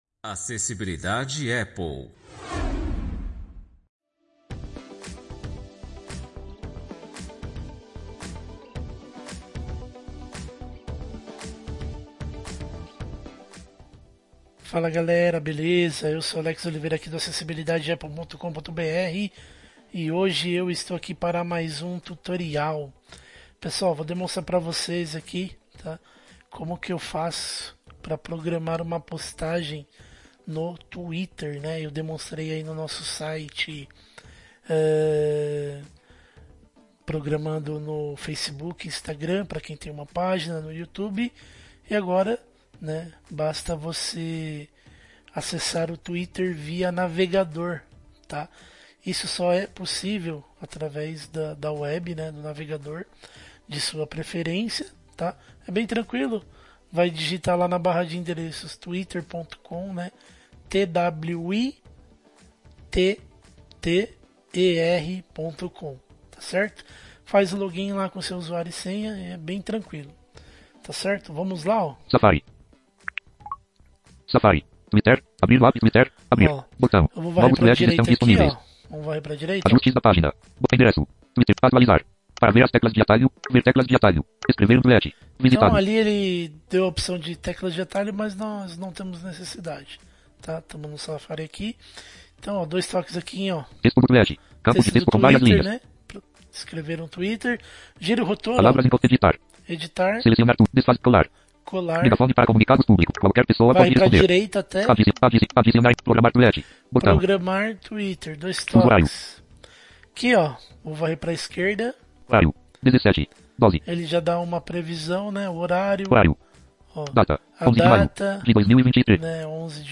Áudio tutorial